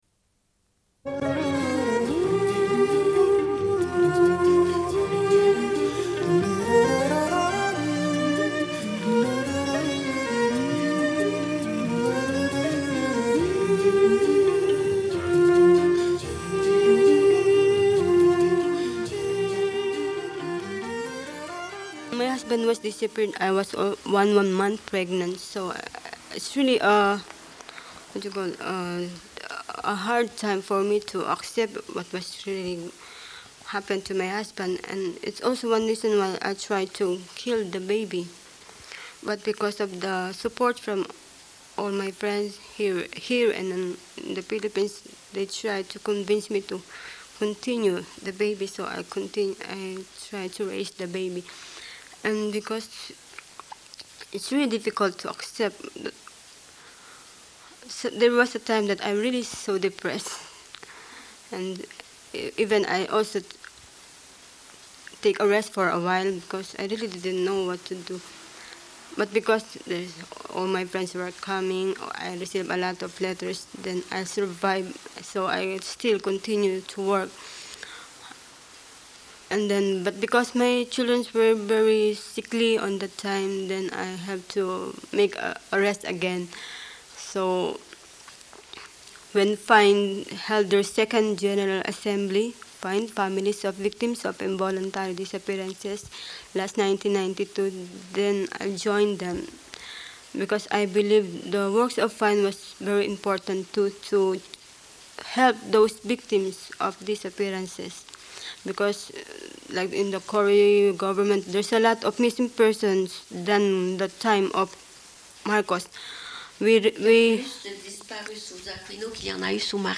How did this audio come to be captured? Début de l'émission manquant.